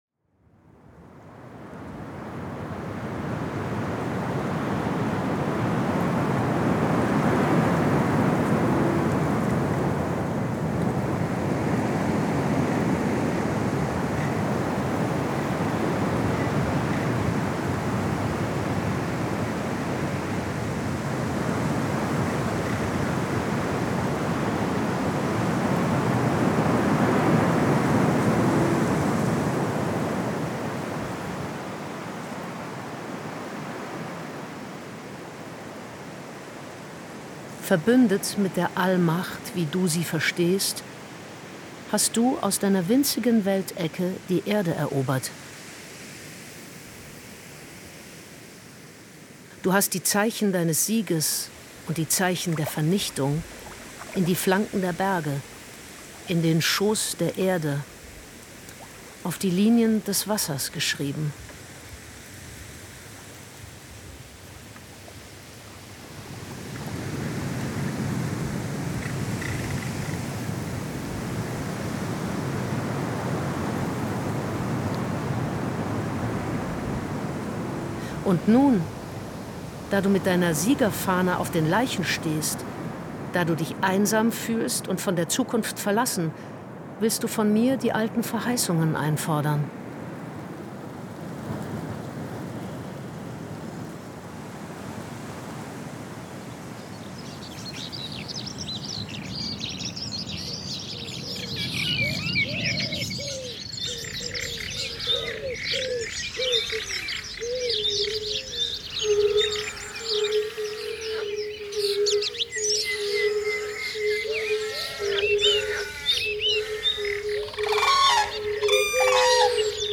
Es ist die Geschichte vom „Wunder von Brodowin“, wie es kam, dass die Landschaft um Brodowin im Biospährenreservat Schorfheide wieder zu einer der artenreichsten Gegenden Deutschlands wurde?, erzählt und hörbar gemacht als radiophone Dokumentation und Klangkomposition aus Landschaftsklängen mit Vogel- und anderen Tierstimmen, Szenen mit menschlichen Akteuren, aufgenommen im Jahreszyklus.